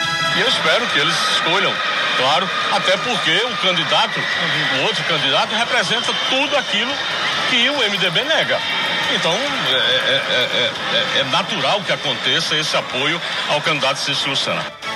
“Eu espero que eles escolham, claro, até porque o outro candidato representa tudo aquilo que o MDB nega. Então é natural que aconteça esse apoio ao candidato Cícero Lucena”, disse em entrevista ao programa Arapuan Verdade, da Rádio Arapuan FM.